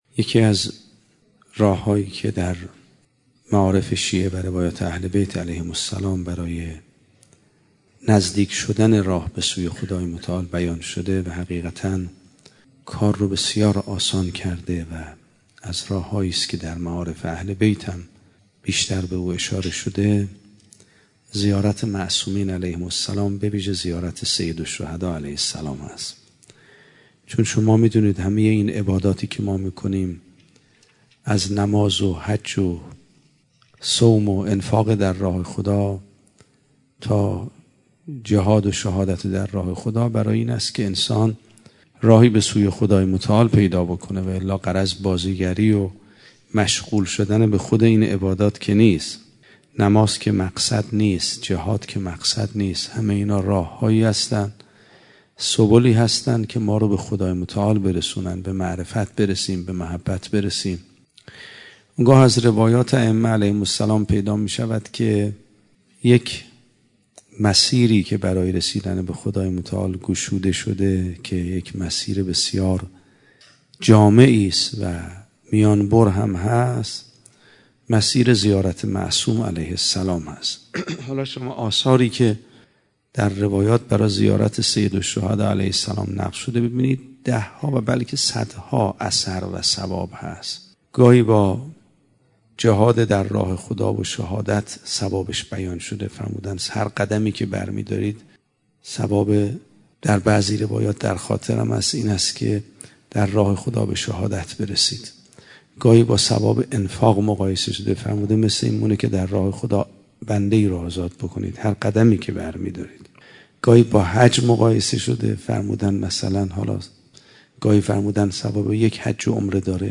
صوت سخنرانی آیت الله میرباقری درباره اهمیت زیاره قبور ائمه معصومین (علیهم السلام)منتشر می شود.